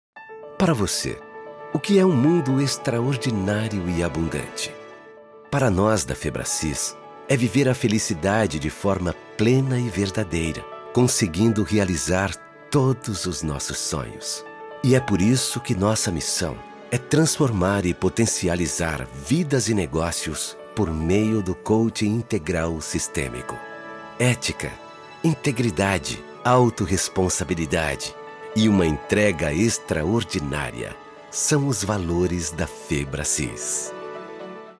Konversation
Sinnlich
Zuversichtlich